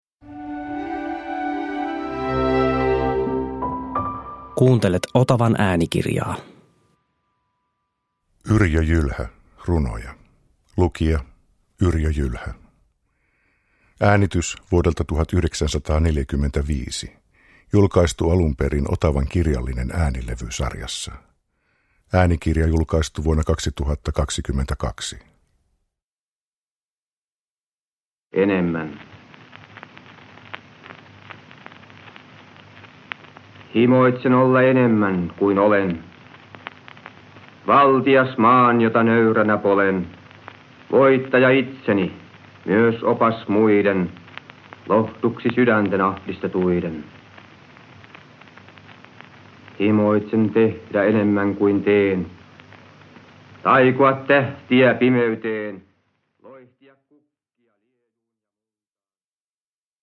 Runoja – Ljudbok – Laddas ner
Yrjö Jylhän runokokoelma Kiirastuli on klassikoksi noussut kuvaus talvisodan eturintamilta. Vain viisi vuotta talvisodan päättymisen jälkeen Jylhä lausui nauhalle sikermän runojaan tästä kokoelmasta sekä kokoelmista Risti lumessa ja Toiviotiellä.
Äänite on julkaistu Otavan kirjallinen äänilevy -sarjassa, jossa ilmestyi yli 70 levyä vuosina 1950-1970.